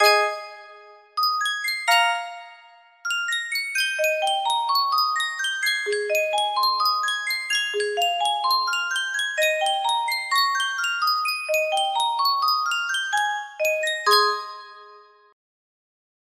Sankyo Music Box - Bach/Gounod Ave Maria HJ
Full range 60